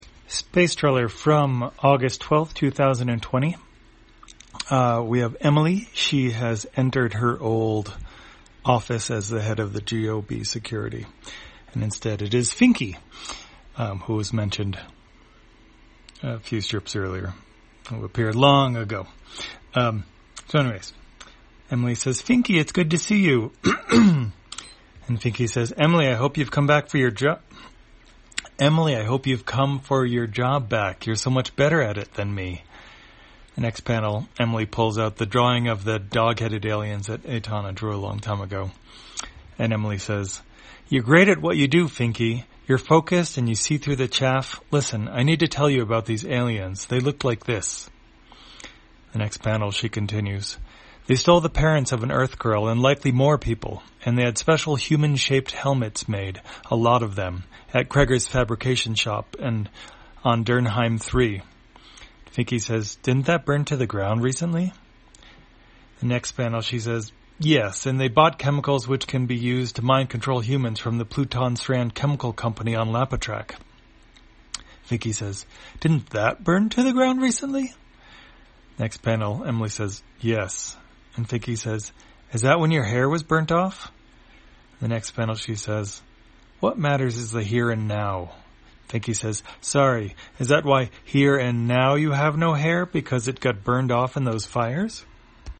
Spacetrawler, audio version For the blind or visually impaired, August 12, 2020.